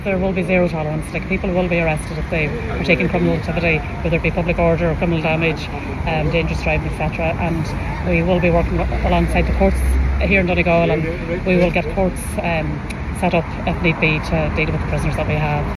Chief Superintendent Goretti Sheridan says there will be a zero tolerance approach taken, backed up with extra court sittings if necessary………..